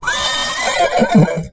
馬のいななきと聞いて思い浮かべるのは「ヒヒーン」という鳴き声でしょう。